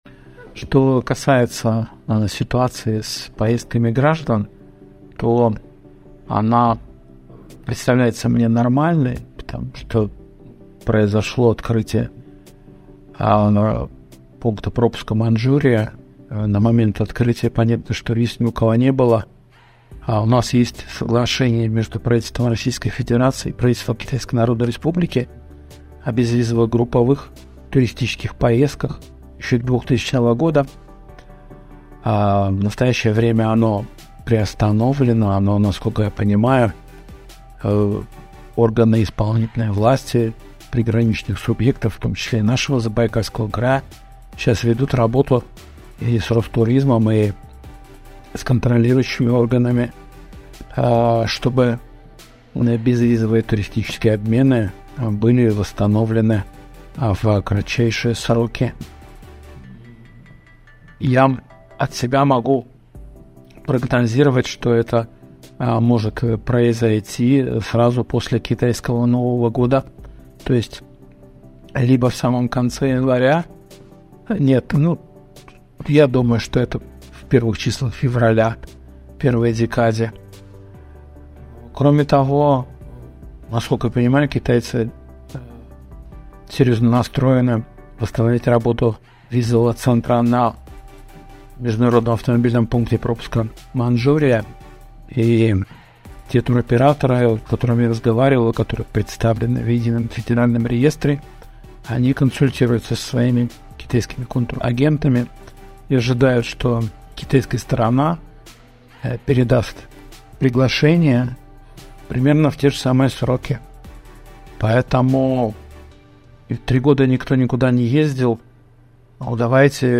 Комментарий